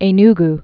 (ā-ng)